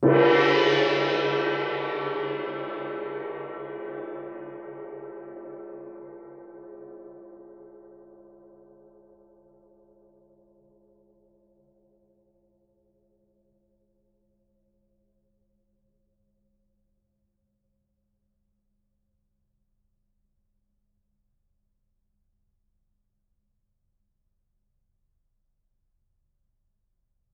gongHit_fff.wav